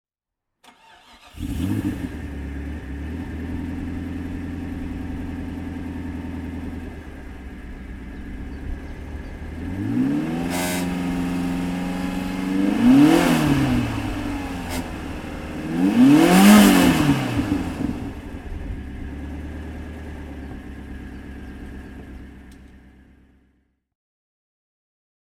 Lotus Esprit V8 GT (1998) - Starten und Leerlauf
Lotus_Esprit_V8_GT_1998.mp3